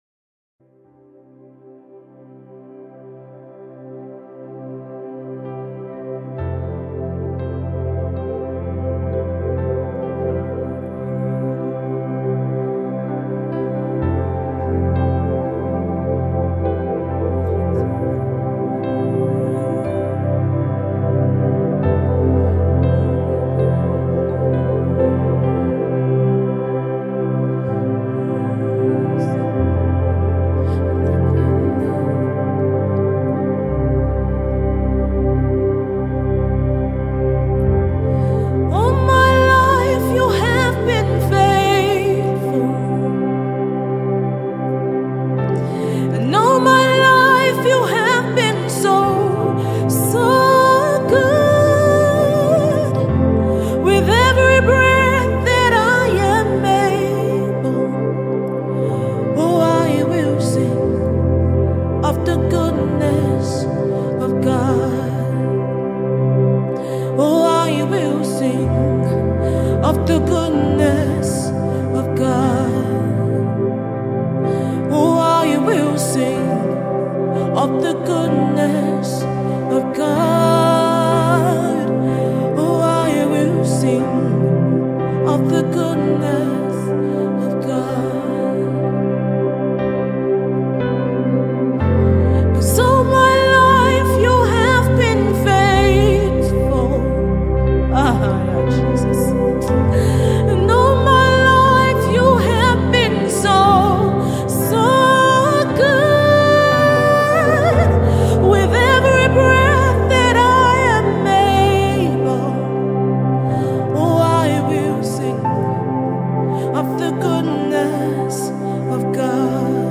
Listen to this beautiful and soul-lifting rendition